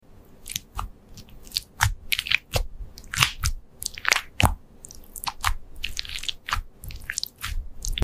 🍍🤏 Squishing a Pineapple Slice?! sound effects free download
Juicy Explosion Incoming!